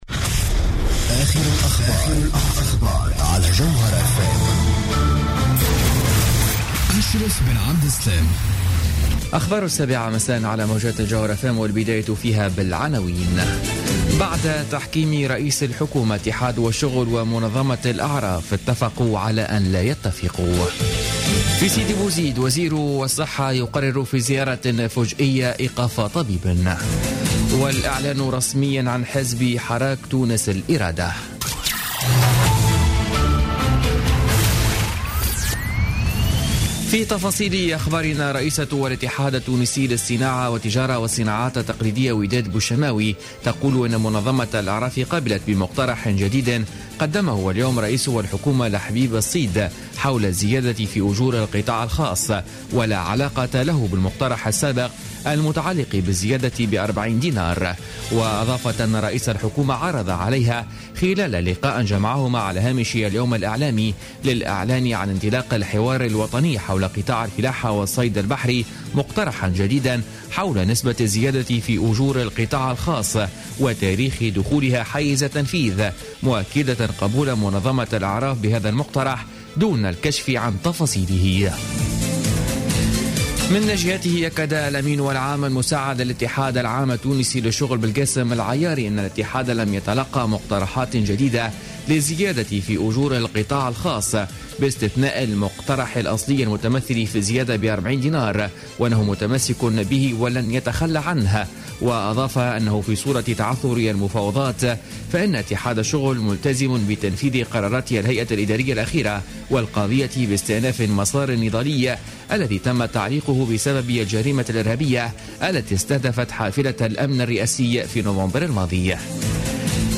نشرة أخبار السابعة مساء ليوم الاربعاء 23 ديسمبر 2015